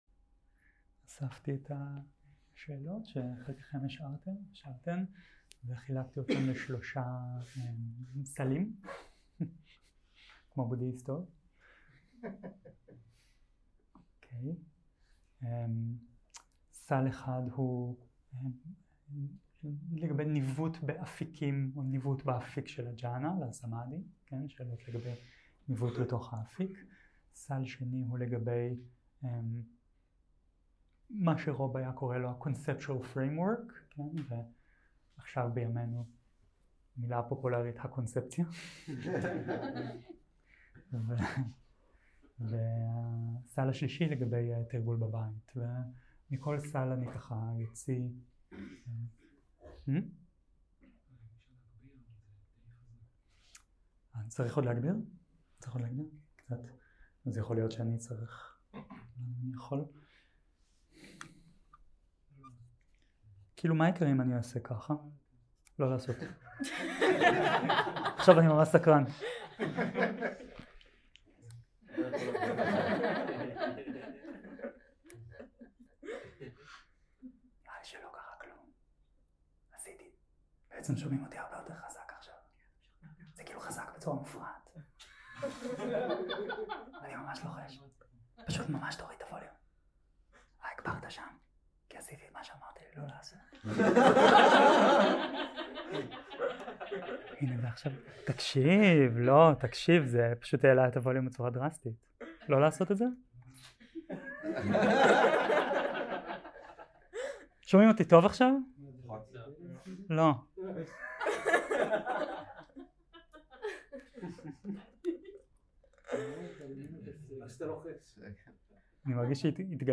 0:00 0:00 סוג ההקלטה: סוג ההקלטה: שאלות ותשובות שפת ההקלטה: שפת ההקלטה: עברית